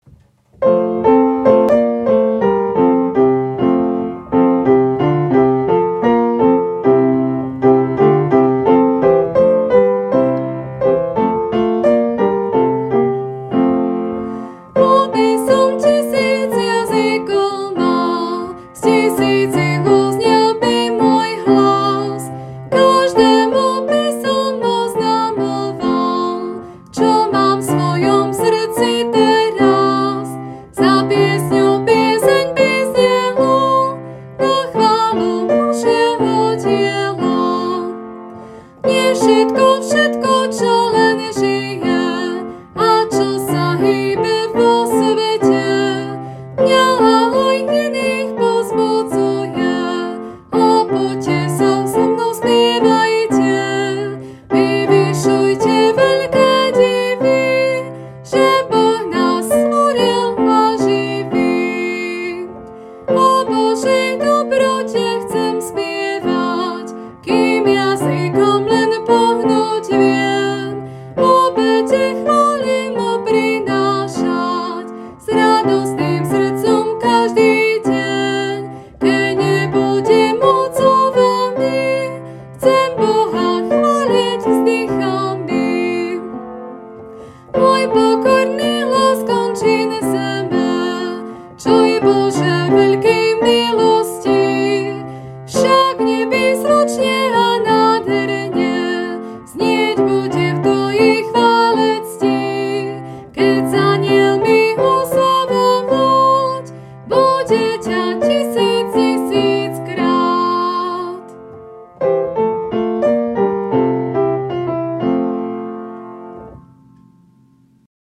1, Pieseň z Evanjelického spevníka: č. 352